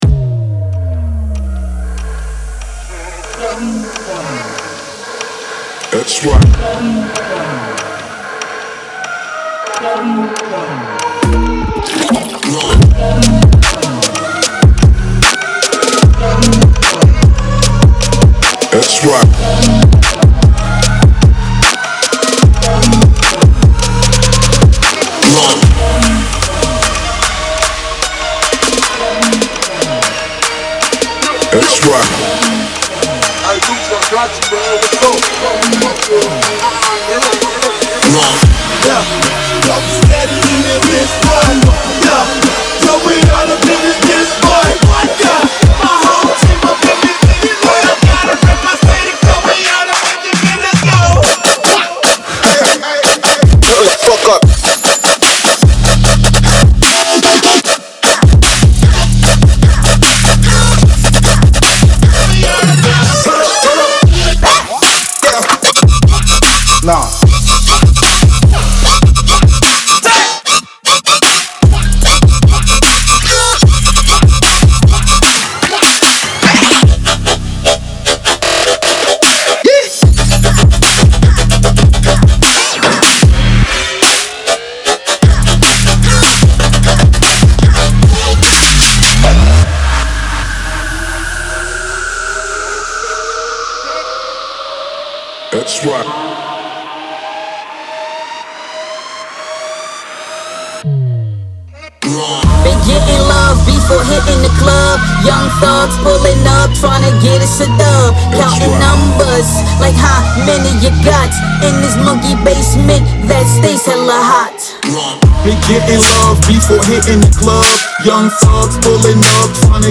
DubstepTrap
与第一个发行版相似，此软件包包含高质量的单次射击，丢球，踢，圈套，快照，疯狂的fx，血清预设等等！
•45鼓循环
•33个合成器环路
•28个808
•20个Vox的样品